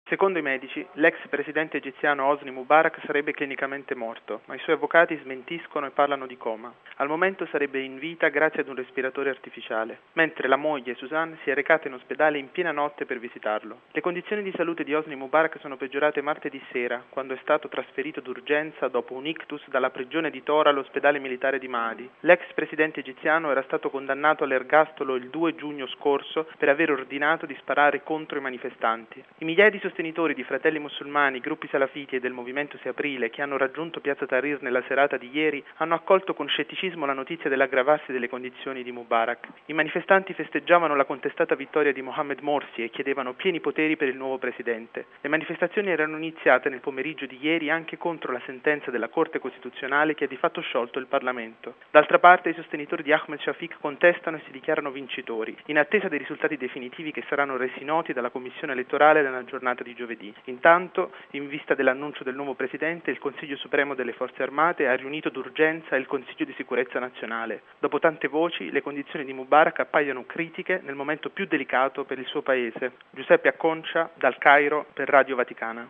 Dal Cairo